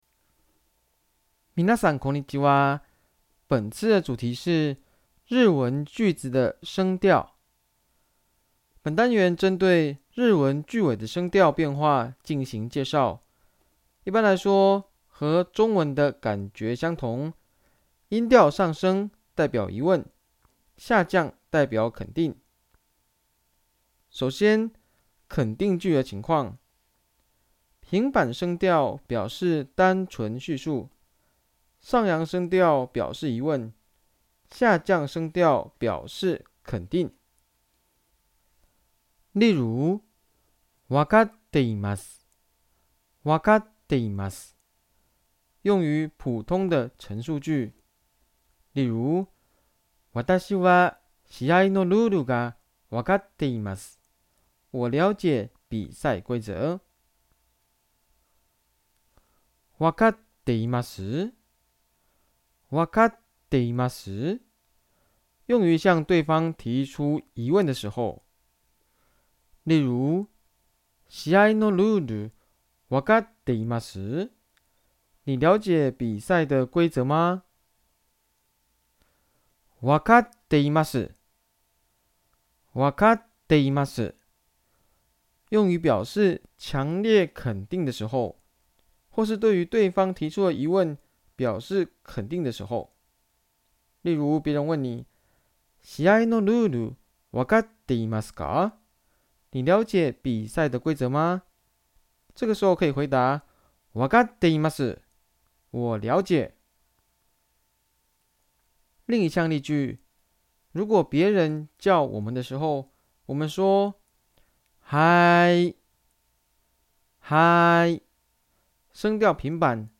聲音解說：